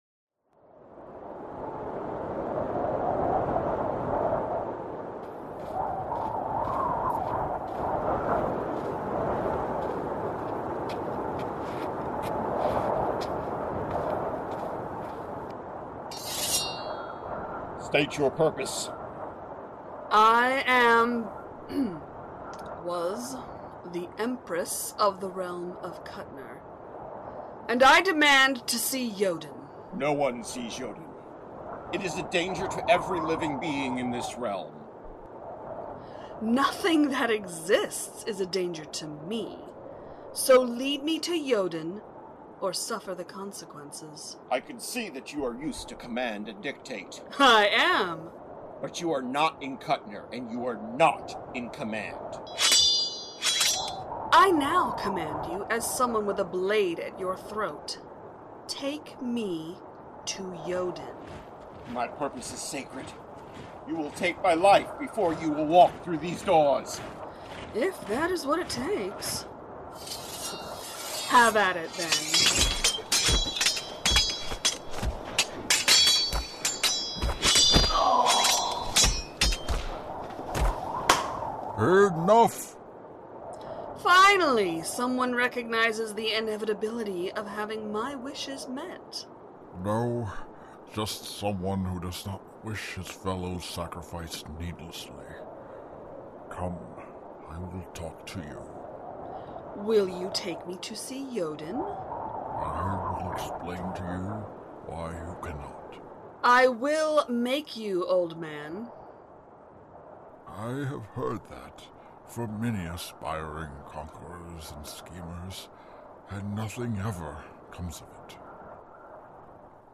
best audio drama